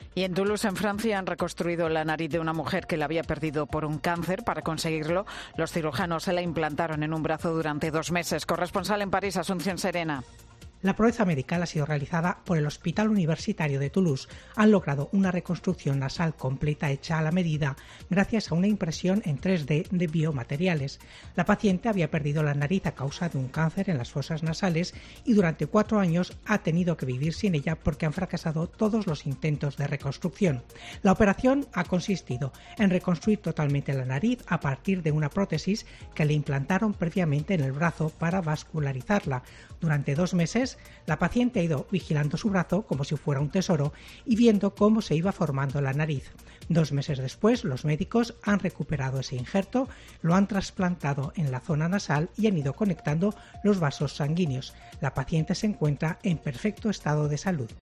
Cirujanos de Tolousse reconstruyen la nariz en 3D a una mujer. Corresponsal en París